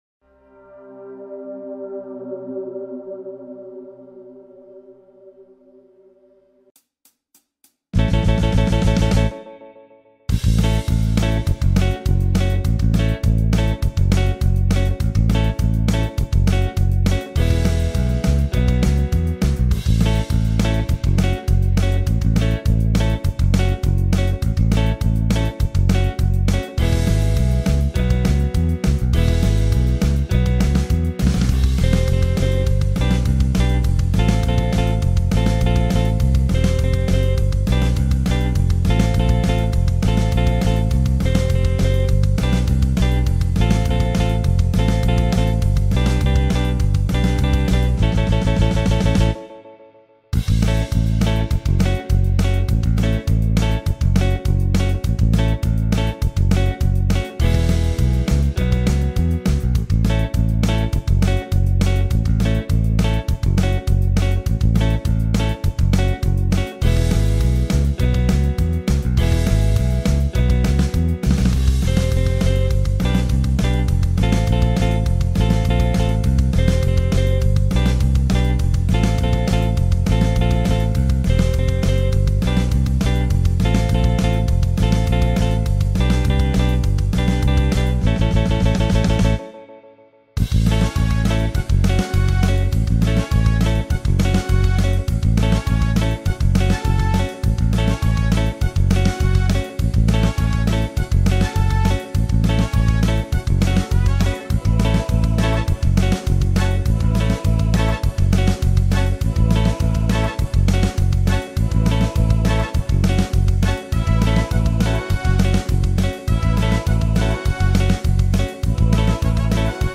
El acompañamiento: